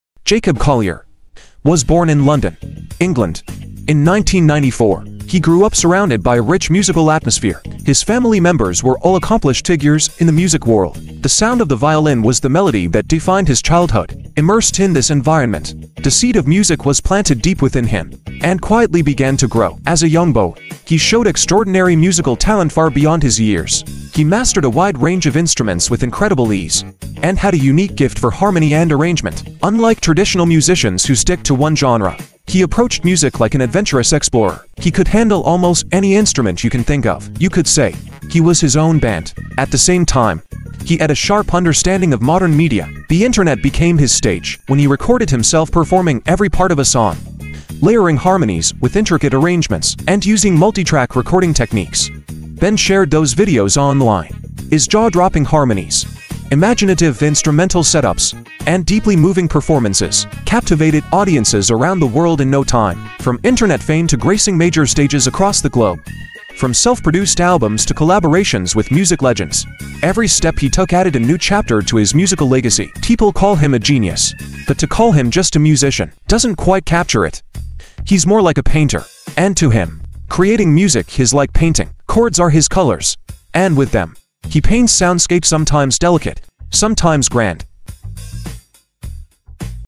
Weave a fantastical musical dreamscape